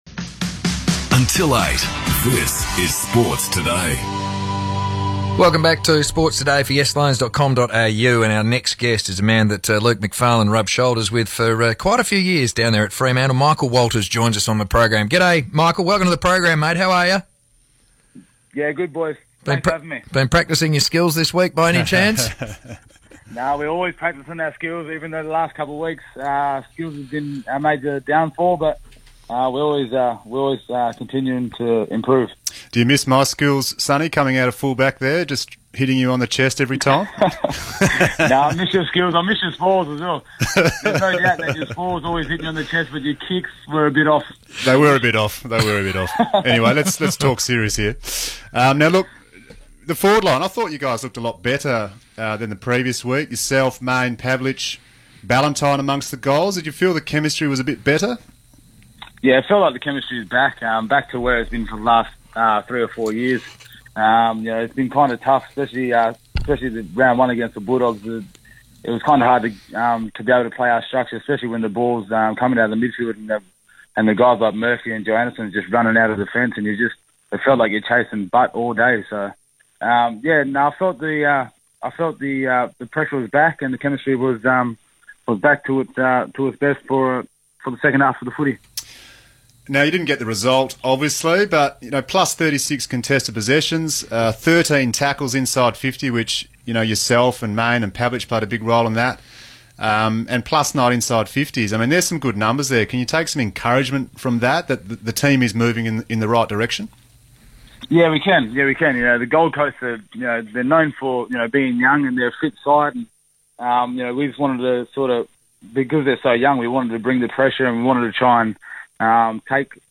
Michael Walters spoke to Sports Today ahead of the Carlton Draught Derby